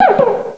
pokeemerald / sound / direct_sound_samples / cries / cubchoo.aif